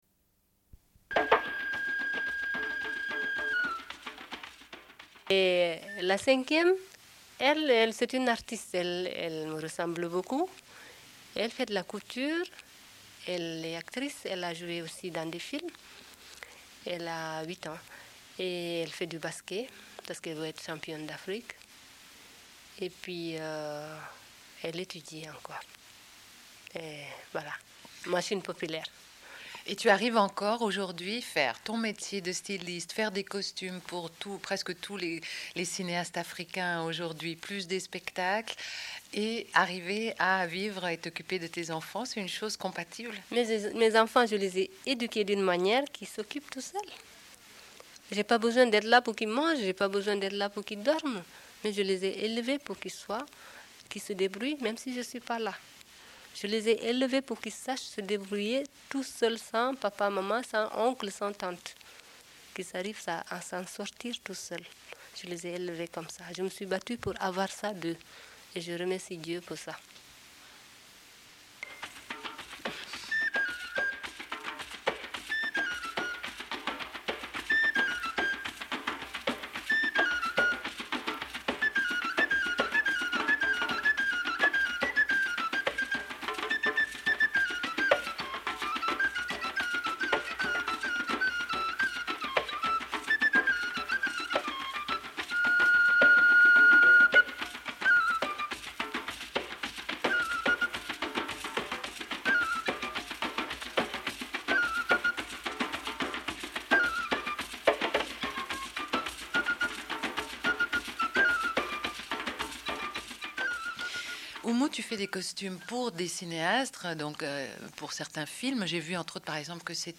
Une cassette audio, face A31:29